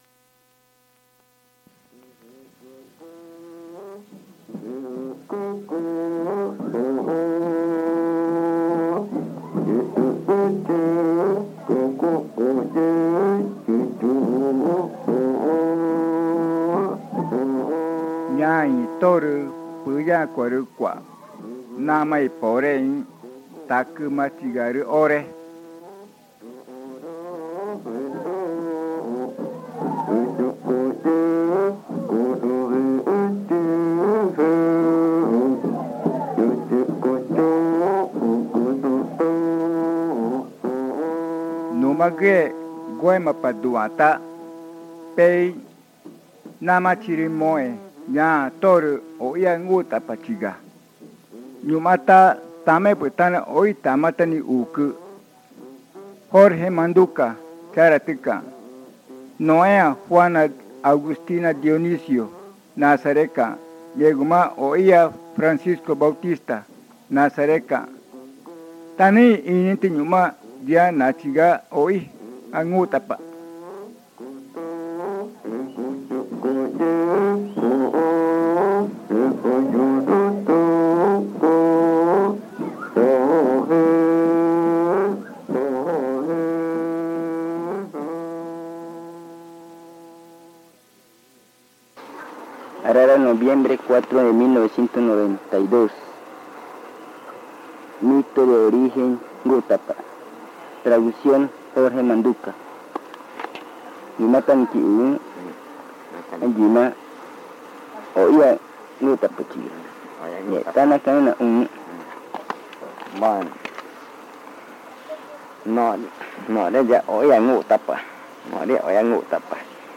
La grabación contiene los lados A y B del casete.